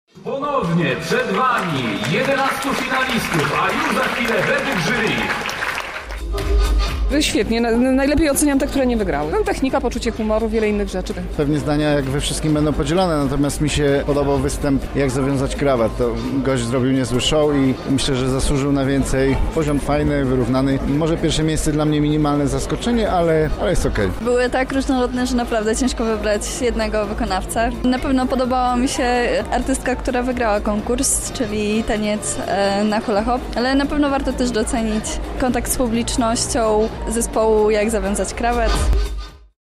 spytał uczestników o wrażenia po finale: